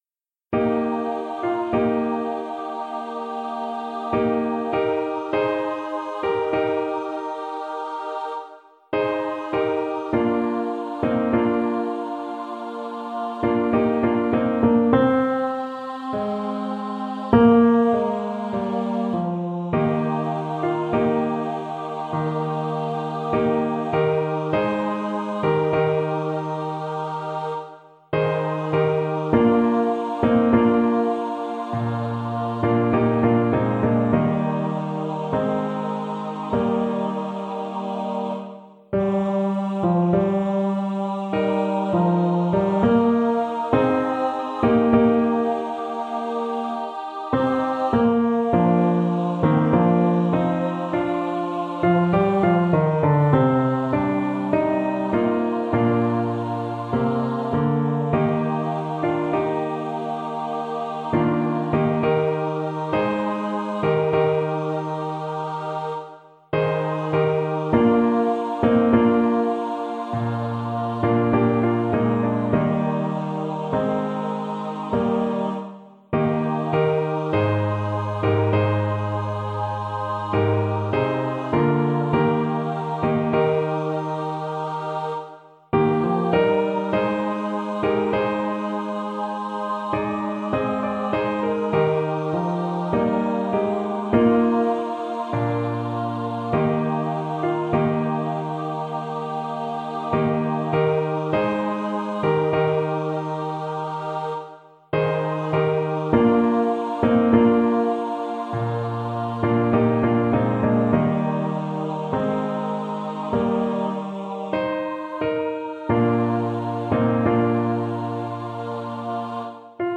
Voicing: SATB, a cappella
Download free rehearsal file:  Mp3    Midi